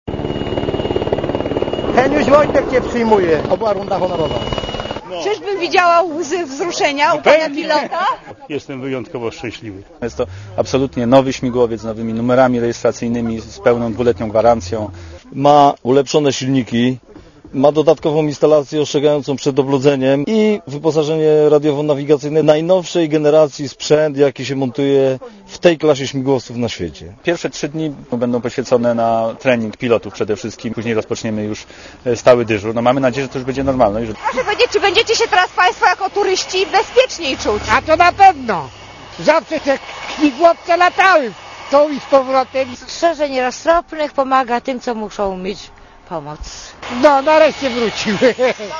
Relacja z Podhala Kapitalny remont Sokoła kosztował 6 mln zł, a to dużo mniej niż kupno nowego.